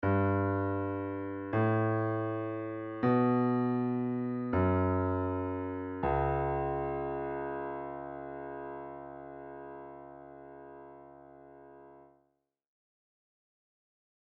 Dark Scary Melodies
When you play a few notes LOW on the piano, they can sound quite dark.
Piano Notes
Play these notes as LOW as you can on the piano
thriller2.mp3